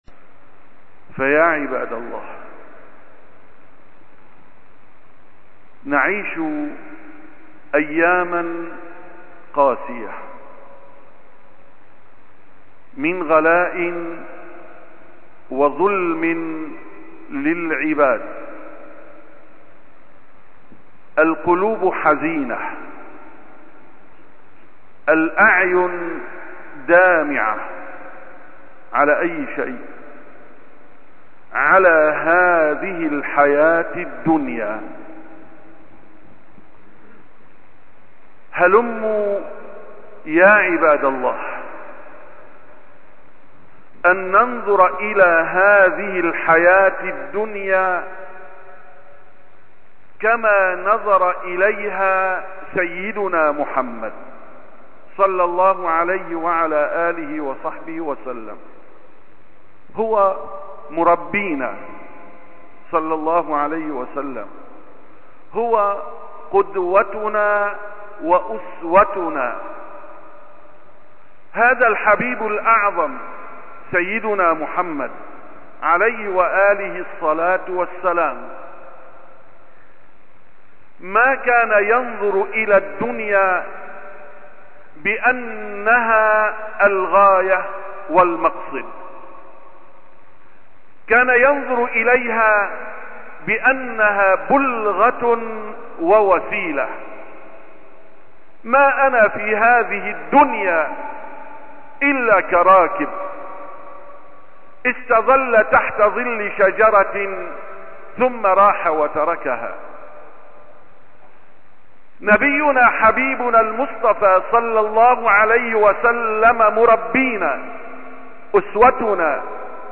776ـ خطبة الجمعة: لا تحزنوا على الدنيا